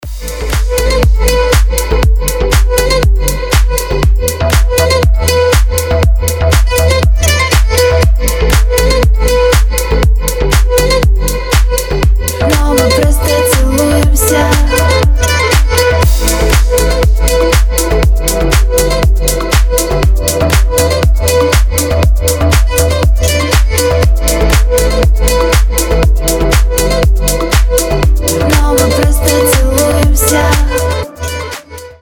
• Качество: 320, Stereo
красивые
deep house
восточные мотивы
dance